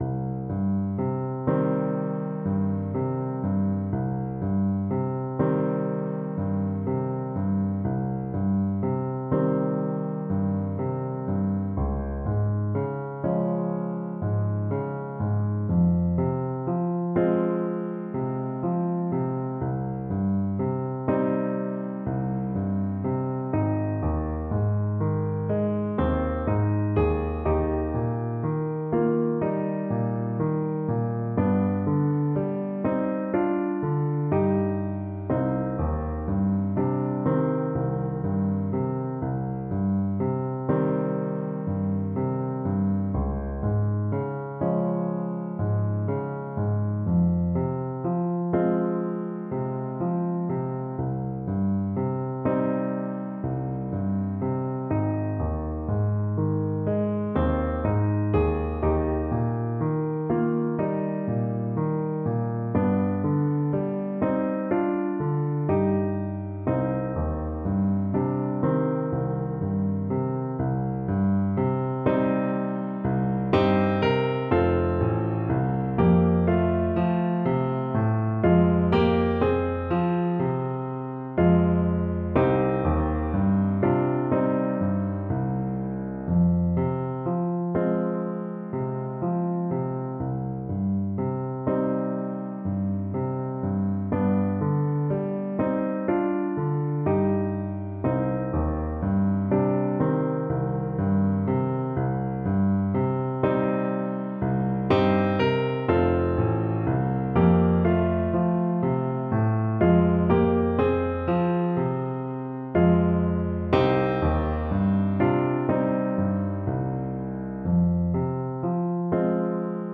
Alto Saxophone
Gently Flowing =c.90
4/4 (View more 4/4 Music)
Classical (View more Classical Saxophone Music)